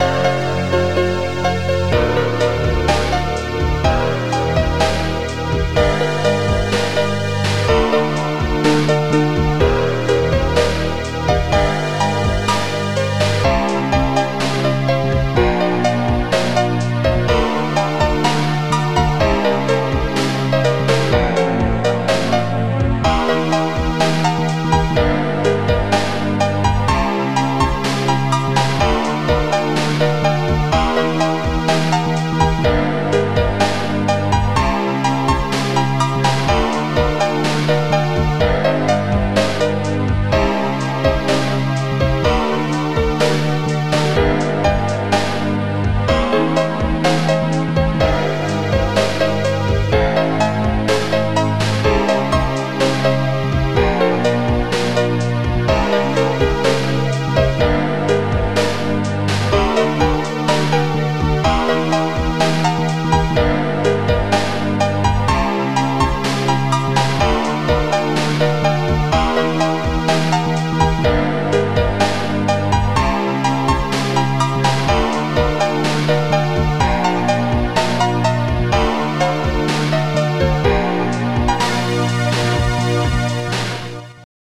Scream Tracker Module
Instruments big bass majeur mineur piano bass drum close hihat snare